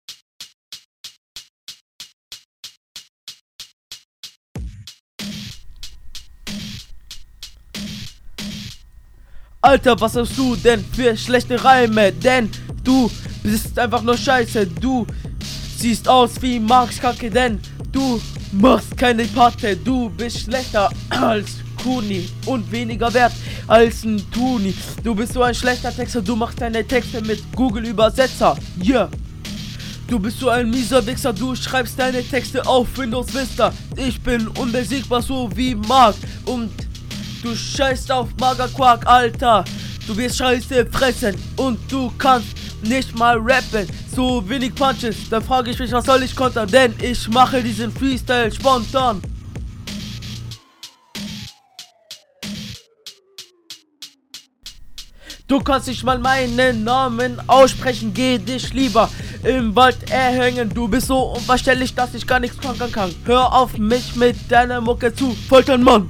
Bei dem Niveau dachte mir schon, dass das ein Freestyle ist...mach das lieber in deiner …